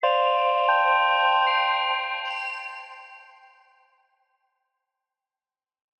QuestComplete.wav